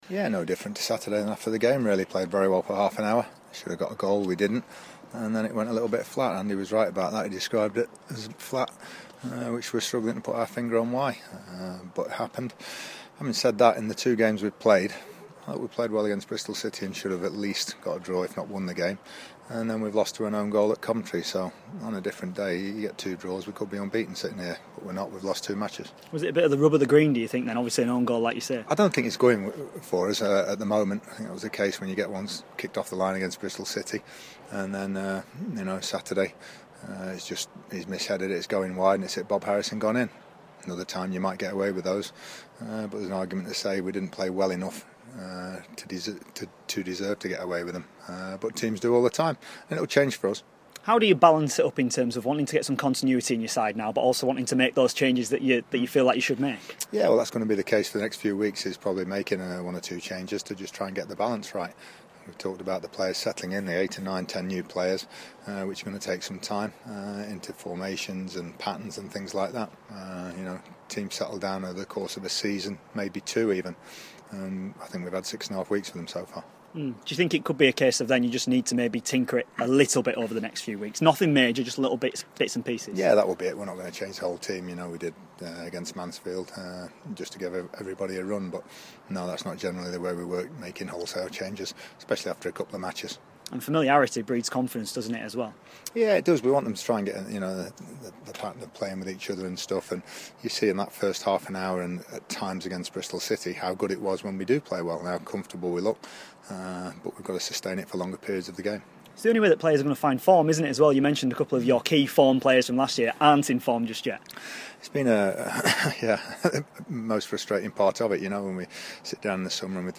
INTERVIEW: Sheffield United manager ahead of their game at Peterborough.